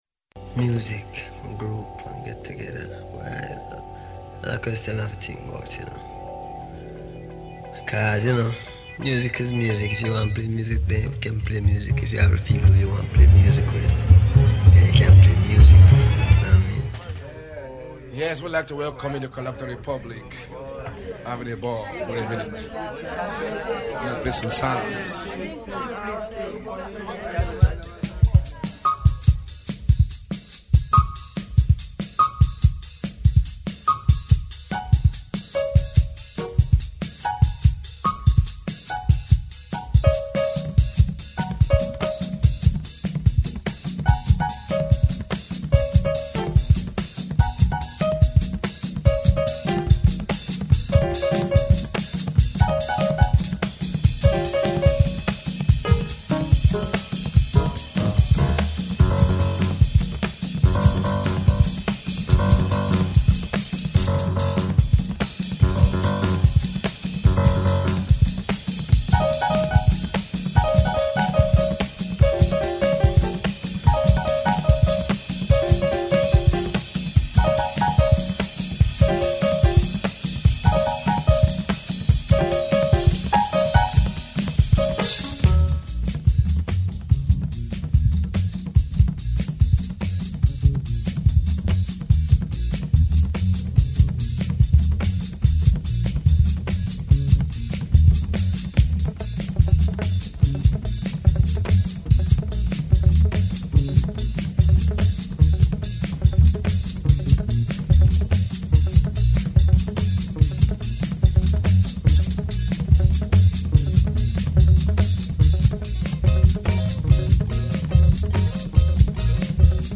FuNkIn soUL.....